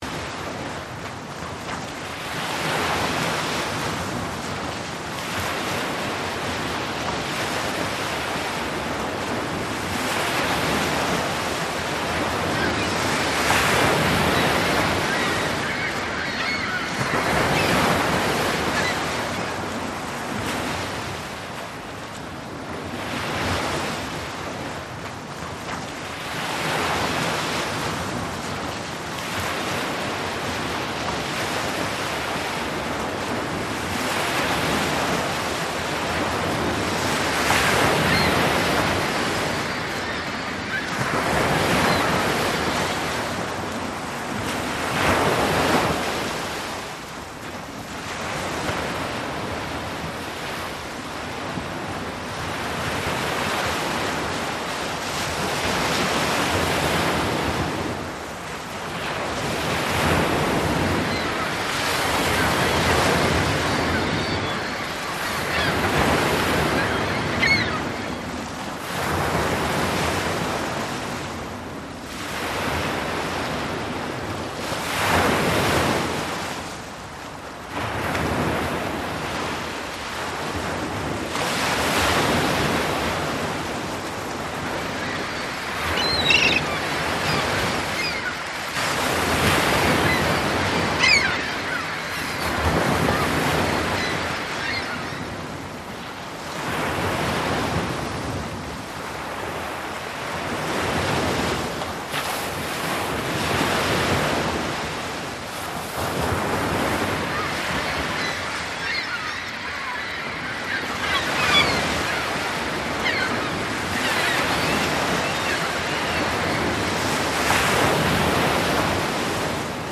Ocean.mp3